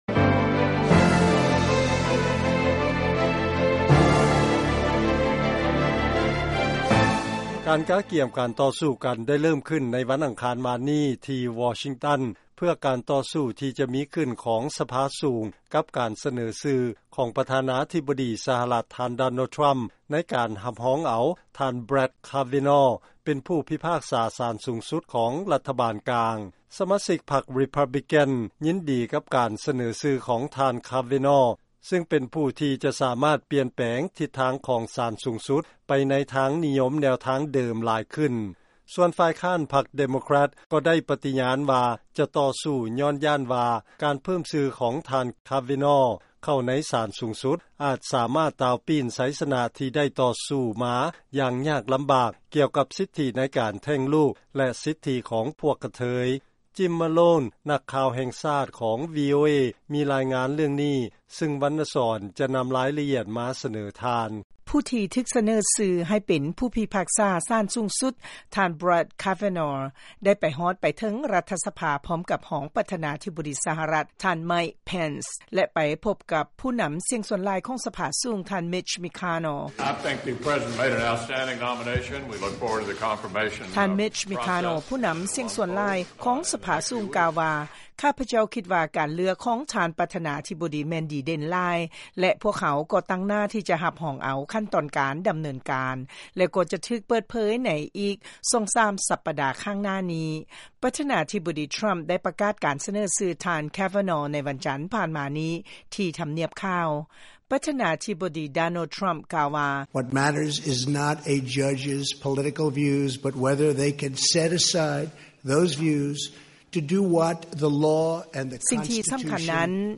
ລາຍງານການຕໍ່ສູ້ກ່ຽວກັບການແຕ່ງຕັ້ງຜູ້ທີ່ຖືກສະເໜີຊື່ເຂົ້າໄປຮັບຕຳແໜ່ງຜູ້ພິພາກສາສານສູງສຸດສະຫະລັດ